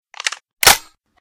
sounds / weapons / librarian_rg6 / reload_start.ogg
reload_start.ogg